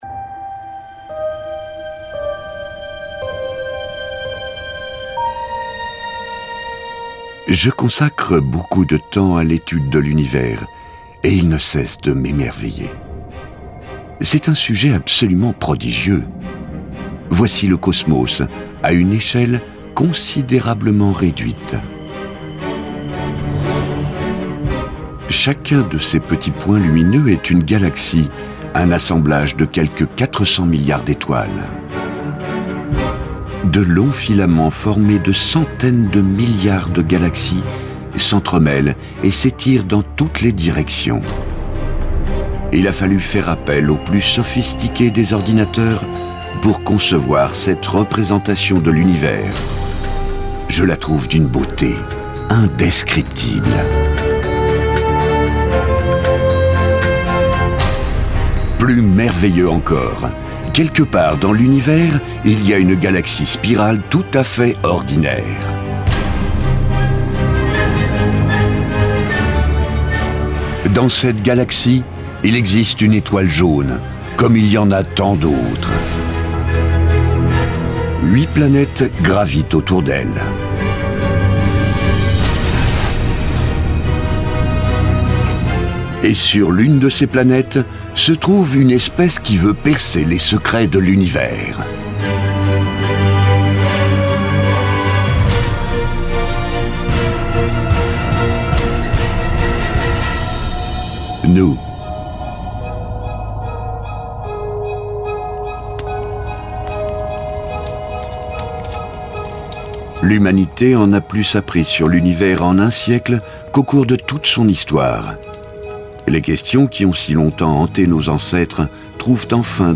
Voix off
Narration
Jovial, chaleureux ou autoritaire au théâtre ou au micro, "à mon insu de mon plein gré", ma voix grave est déterminante, aussi ai-je appris à en sortir))).